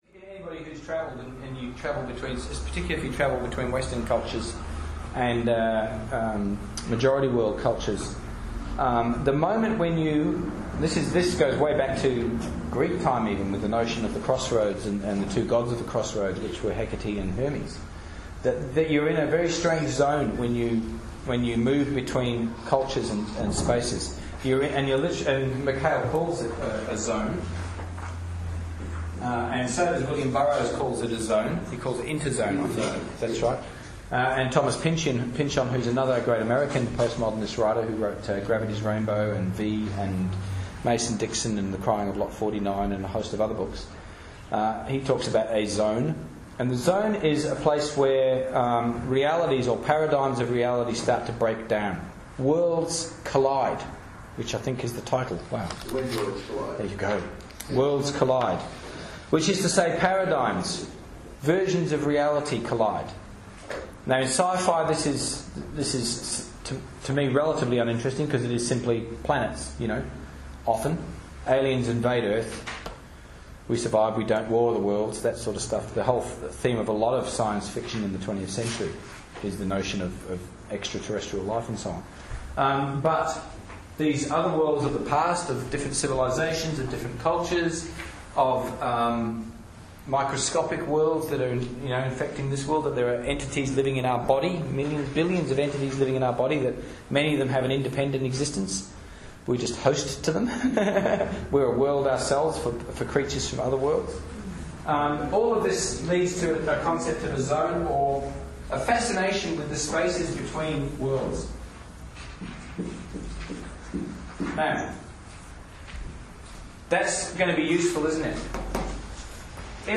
This short sample podcast has been taken from a workshop delivered in 2011 outlining the relationship between postmodern writing techniques and post WWII social, economic and cultural developments (e.g. 'postmodern society' and 'globalisation').